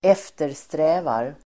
Ladda ner uttalet
Uttal: [²'ef:ter_strä:var]